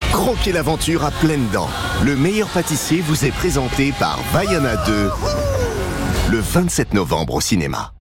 Voix off masculine posée et dynamique pour "Vaiana 2" de Disney
Punchy, événementiel et posé.
Billboard avant « Le meilleur pâtissier » sur M6.
Enregistré chez Badje.
Pour ce billboard, mon interprétation dans la voix off devait allier le côté posé et sûr de soi de Disney et du film Vaiana 2.
L’approche pleine d’énergie du jeu permet d’être dans l’action de l’histoire.